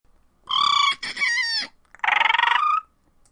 Download Dinosaur sound effect for free.
Dinosaur